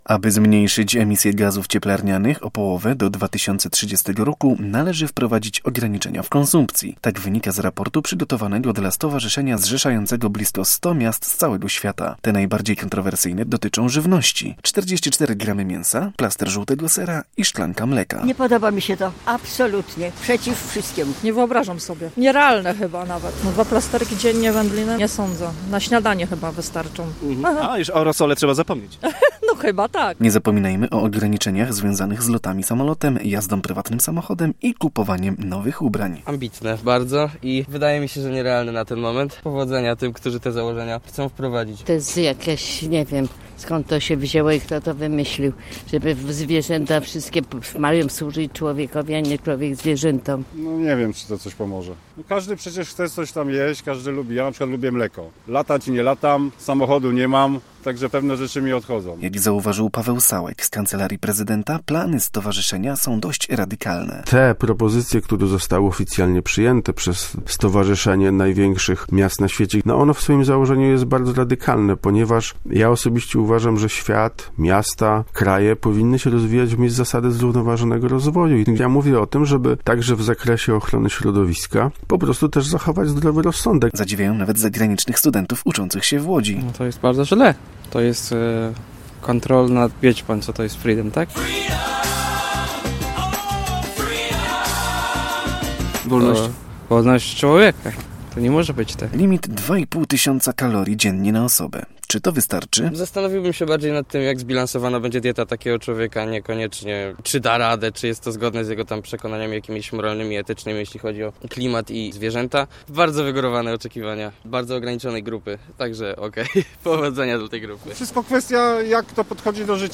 Zapytaliśmy łodzian, co sądzą o założeniach raportu.
Łodzianie negatywnie wypowiadają się o takim pomyśle.